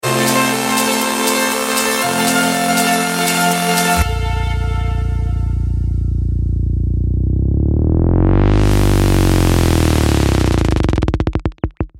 Das schneller und langsamer werden